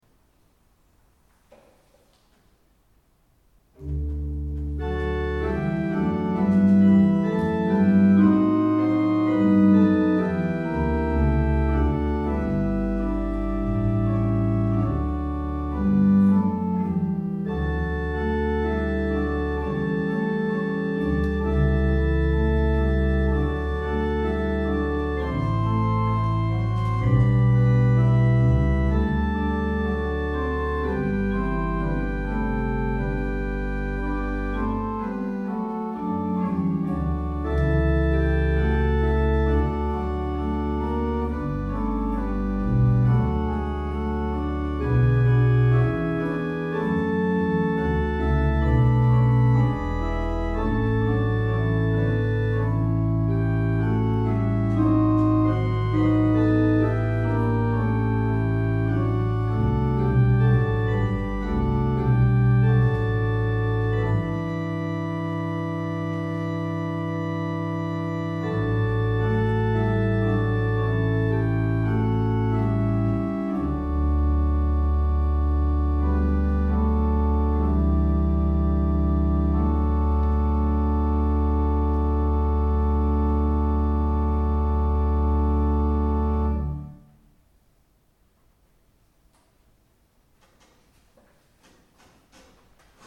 Bordenau Ev.-luth. St. Thomas-Kirche
Stimmung nach Bach-Kellner
Klangbeispiele dieser Orgel